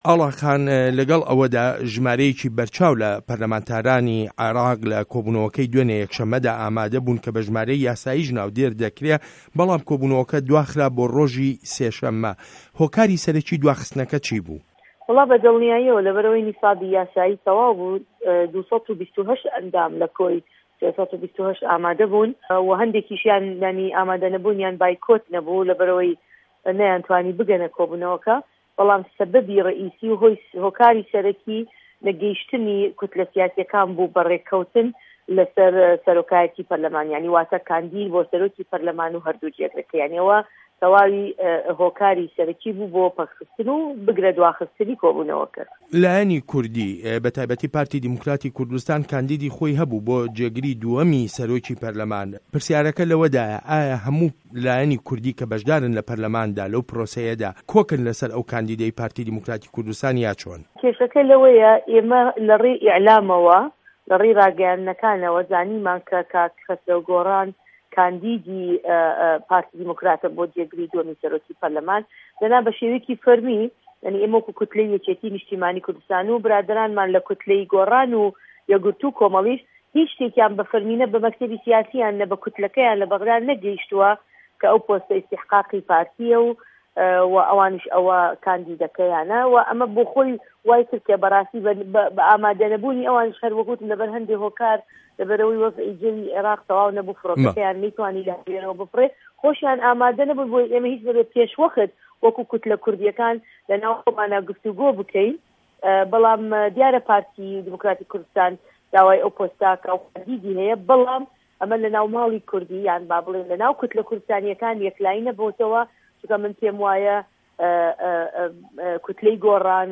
وتووێژ له‌گه‌ڵ ئاڵا تاڵه‌بانی